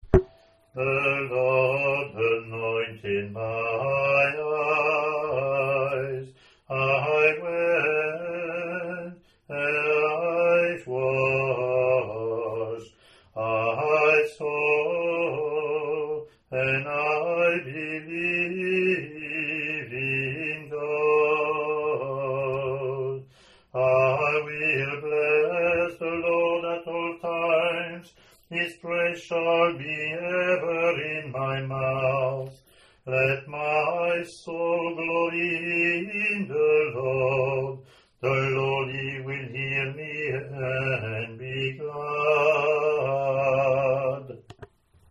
English antiphon – English verseLatin antiphon)